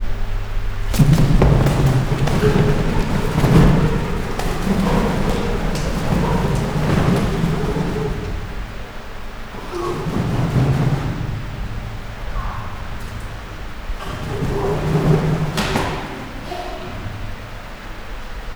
zartterbentavolabbcsobogoviz_szelvedo00.18.wav